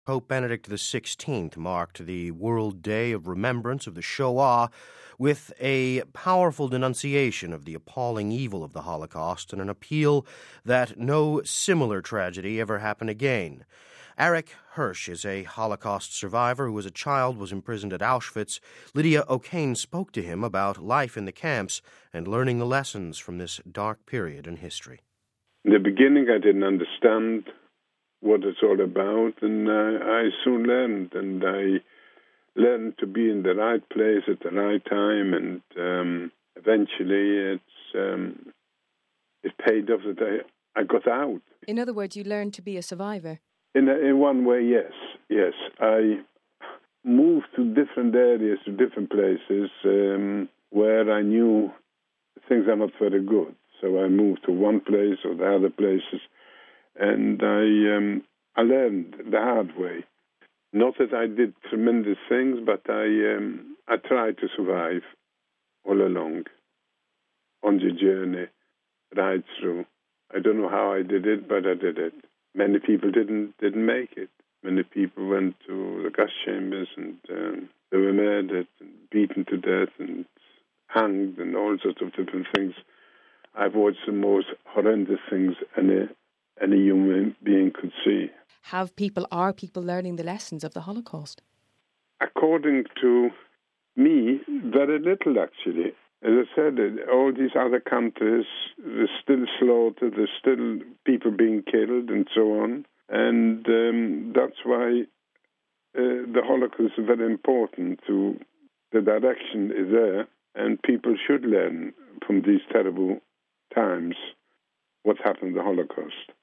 Holocaust Survivor Remembers Life in Camps